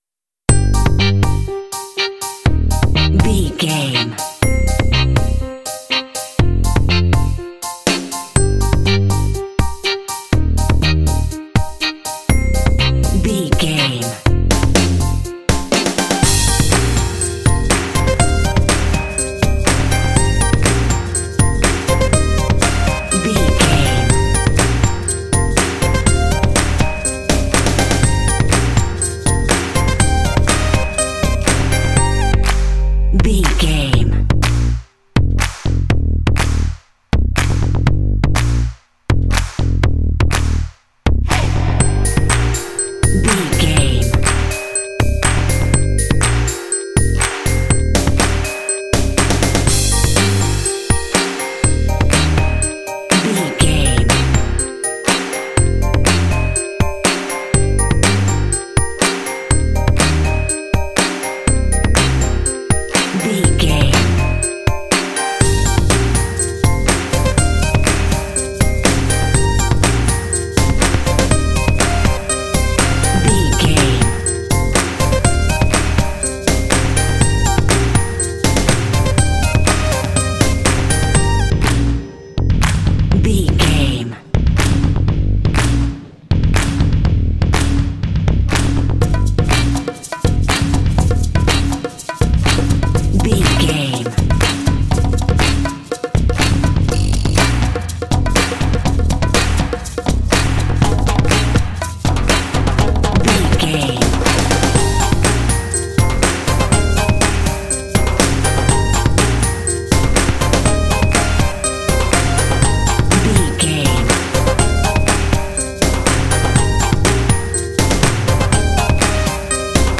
Uplifting
Aeolian/Minor
happy
piano
electric guitar
bass guitar
drums
synthesiser
conga
Funk
soul
groove